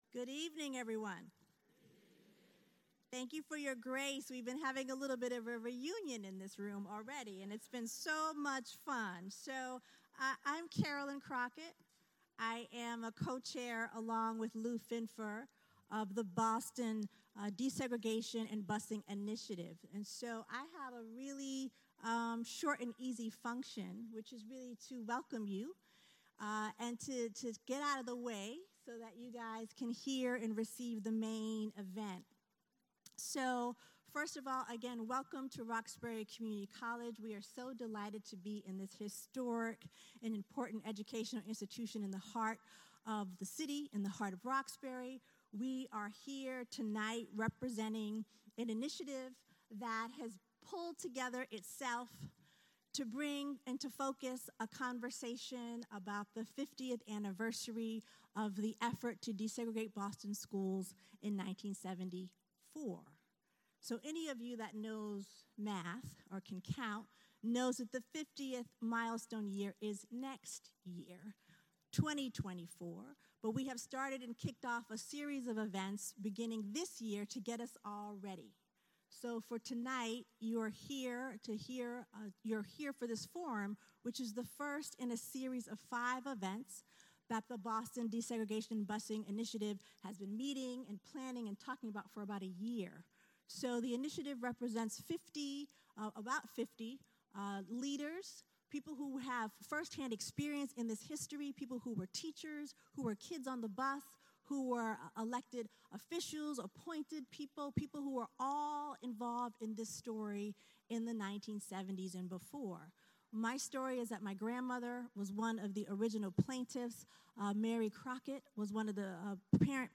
This program will be presented at Roxbury Community College, Media Arts Building, 1234 Columbus Avenue, Roxbury Crossing, 02120